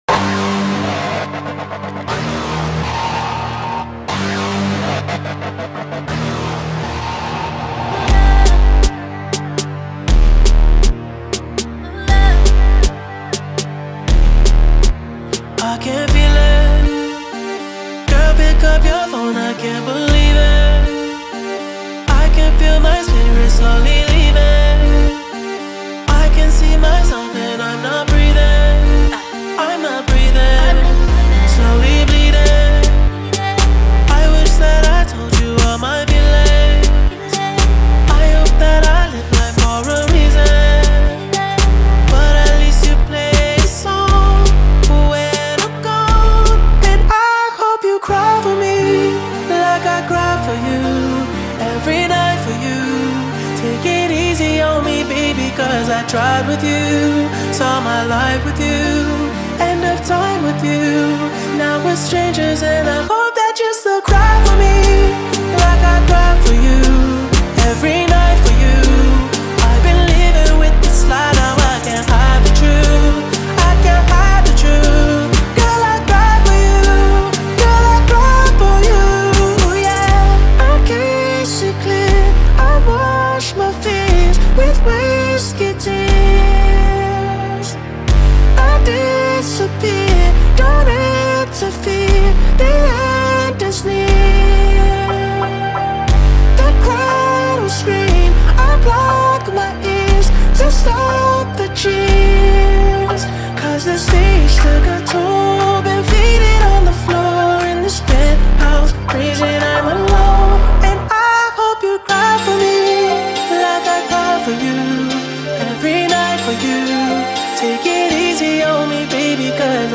ساخت فضایی اتمسفریک
لحن ناراحت کننده هست و وکال ها تکرار میشه